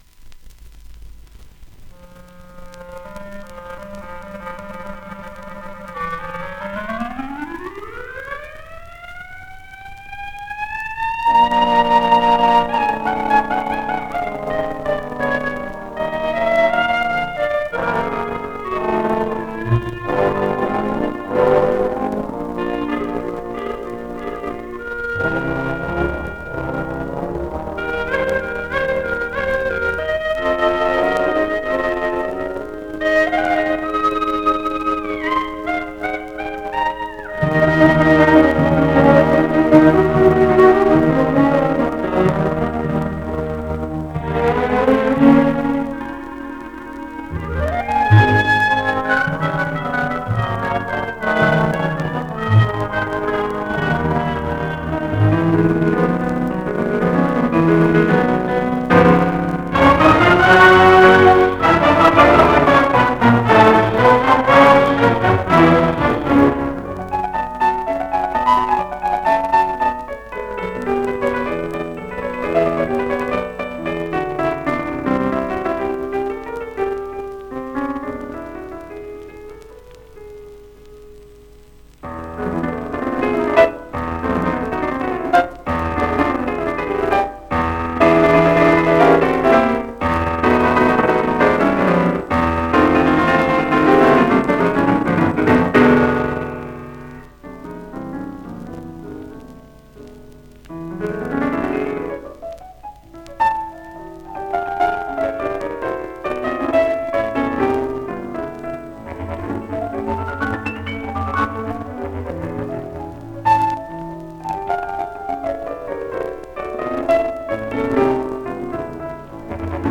1 disco : 78 rpm ; 30 cm Intérprete